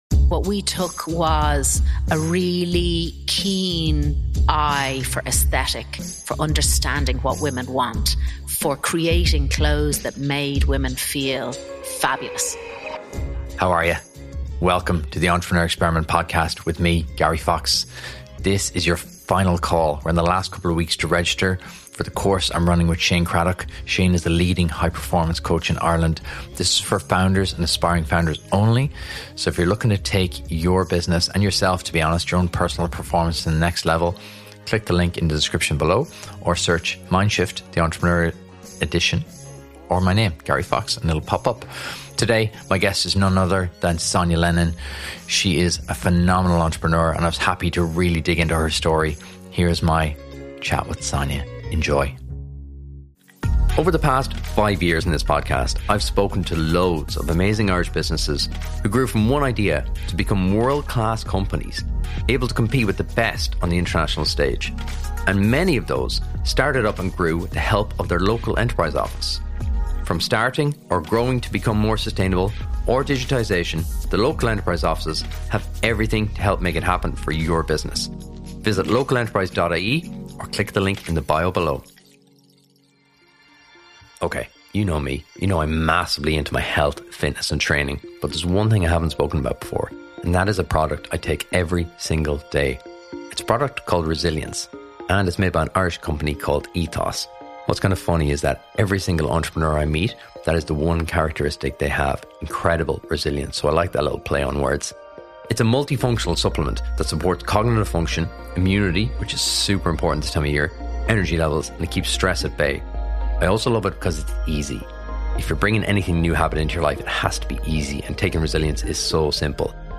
Join the conversation with the indomitable Sonya Lennon, as she shares the riveting story of her entrepreneurial voyage, which weaves together fashion innovation and profound social impact.